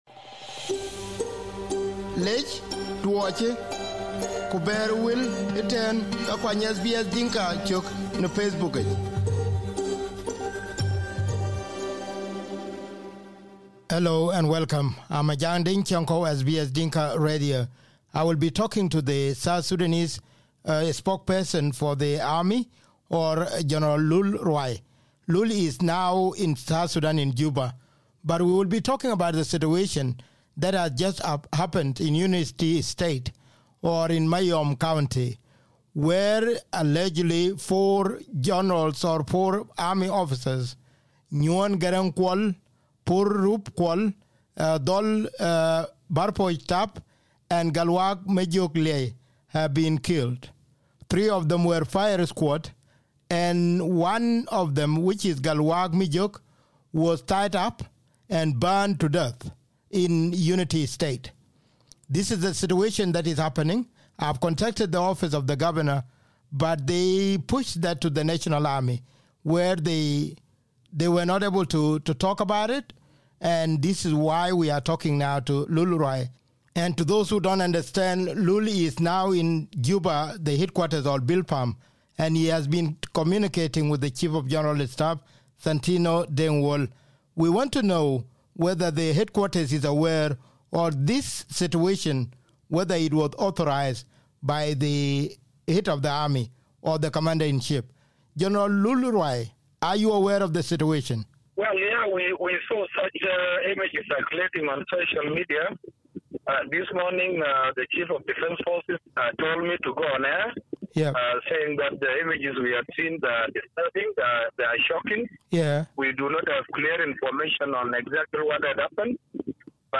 Here is the interview with Lul a few minutes ago.